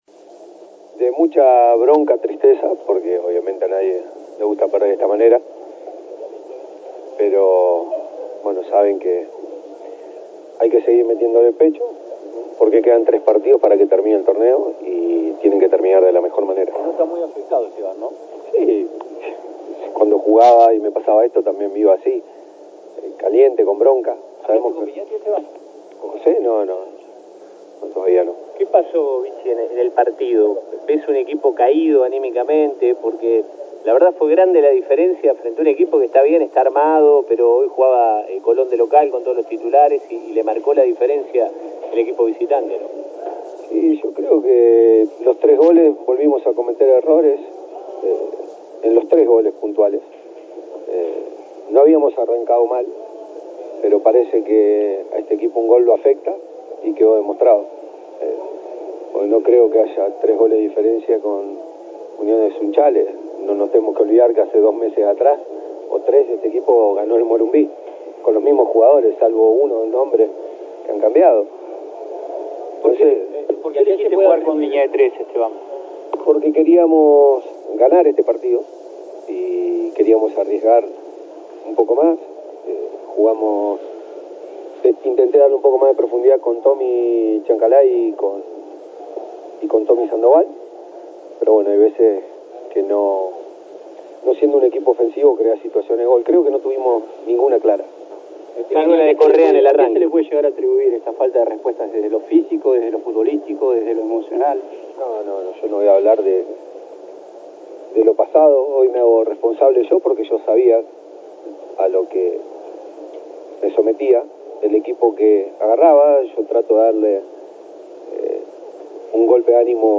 Conferencia de Prensa, Esteban Fuertes (dt interino colón)